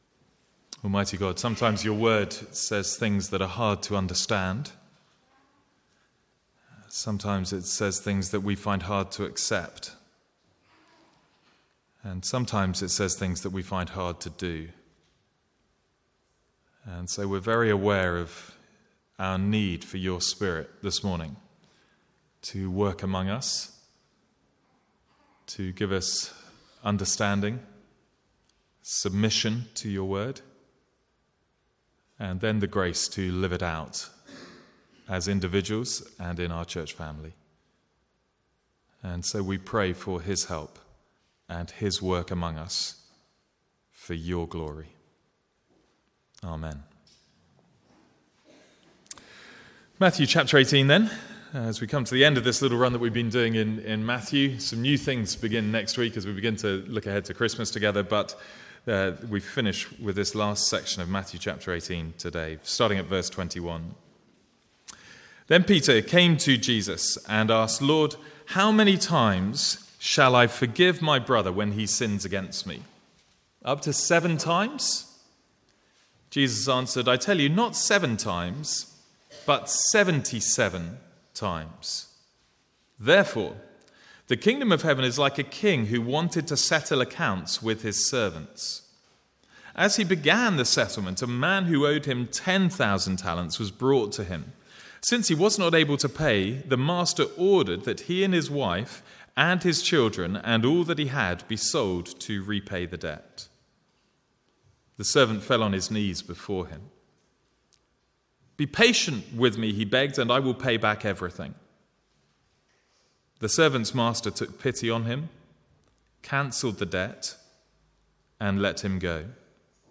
From the Sunday morning series in Matthew.